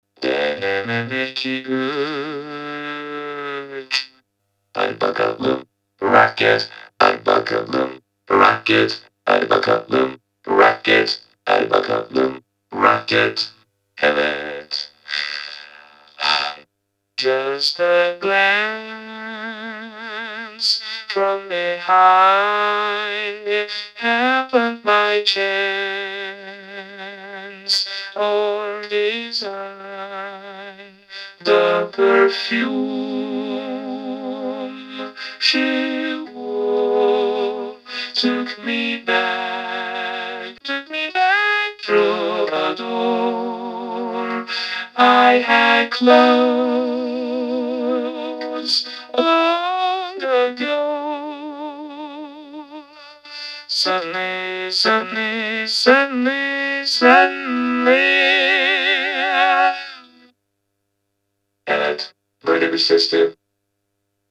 Microkorg'da Herbie Hancock Gibi Anlaşılır Vocoder Yapmak
Selam Değerli Müzisyen Kardeşlerim Abilerim Microda Yazdığım Yeni Vocoder Sesin'e Bir Bakın.